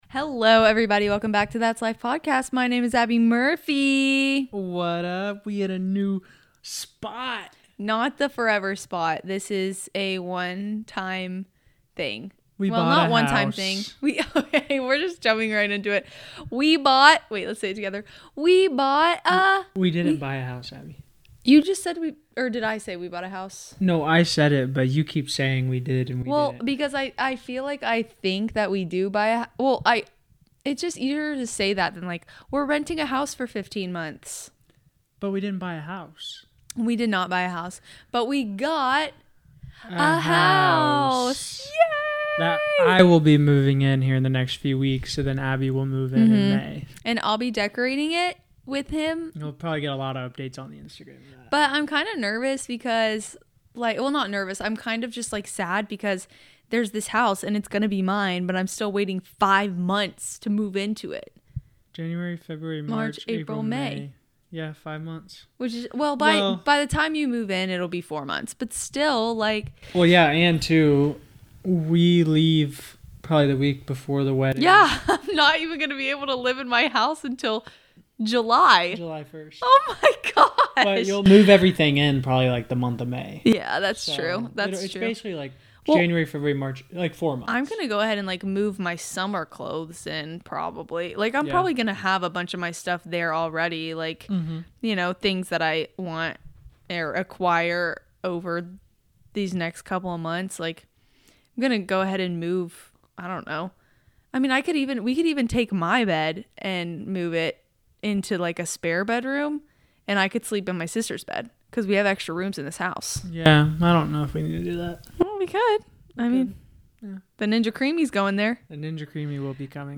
We are so happy you're joining us as we are in a traditional studio (lol) talking ALL about our new house, our NYC trip, and what our version of the 75 hard looks like and why we are doing it! We also dive into wedding planning/ changes, and answer some of your questions about working out on vacation, finding a sense of self when out of town, and more!